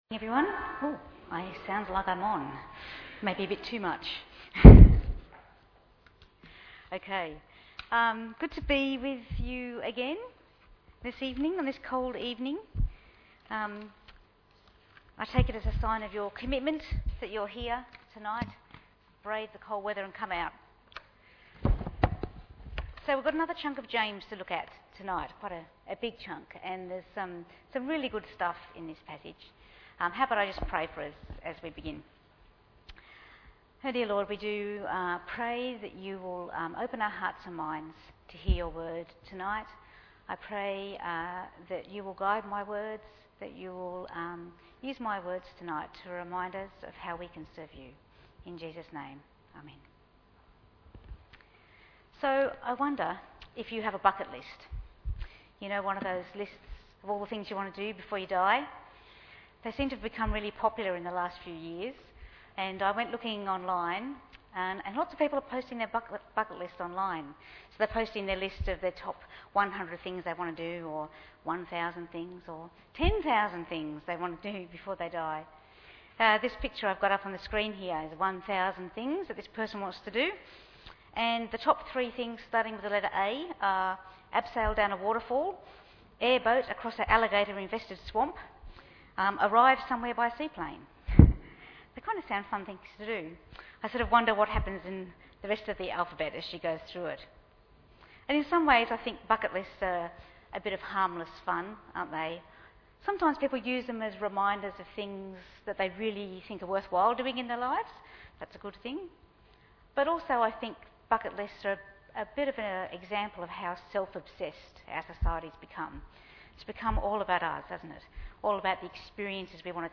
Bible Text: James 4:13 – 5:11 | Preacher